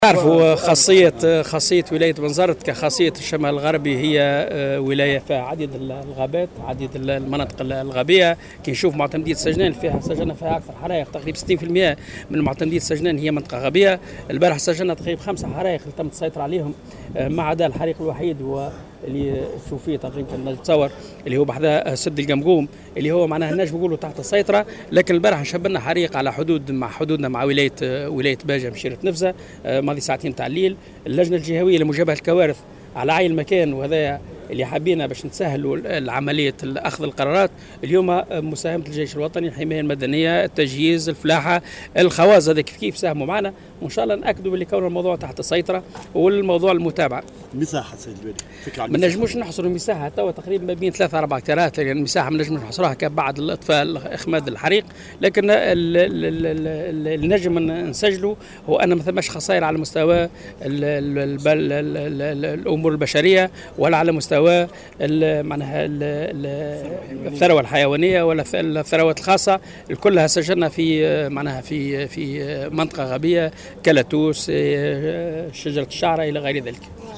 اكد والي بنزرت محمد قويدر في تصريح لموفد "الجوهرة اف أم" اليوم الثلاثاء أن الوضع تحت السيطرة بشكل عام على اثر الحرائق التي اندلعت في بعض المناطق من ولاية بنزرت وأساسا في سجنان.